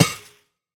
break2.ogg